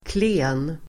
Uttal: [kle:n]